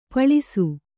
♪ paḷisu